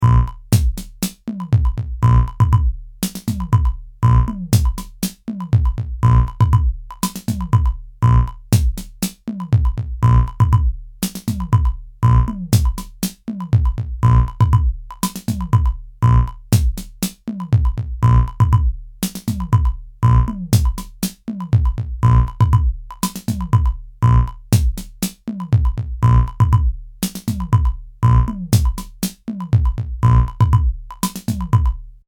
Bucle de percusión electrónica
Música electrónica
melodía
repetitivo
sintetizador